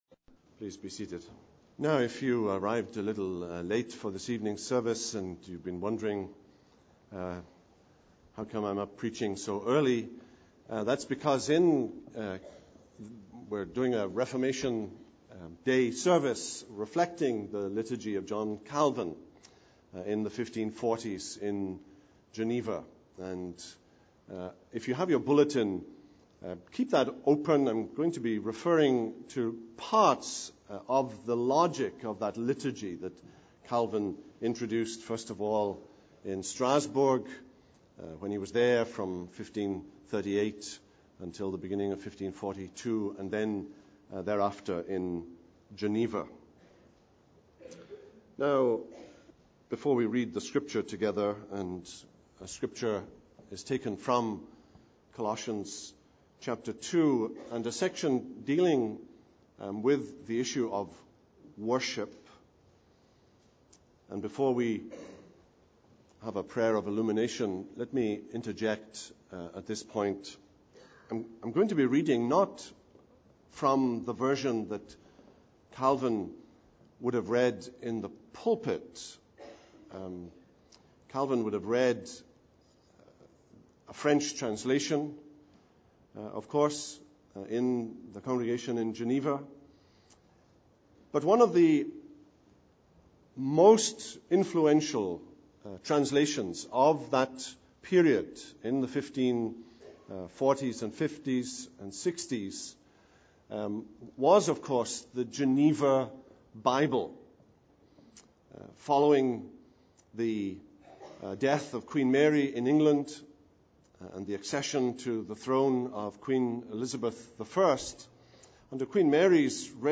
The Lord’s Day Evening November 19, 2006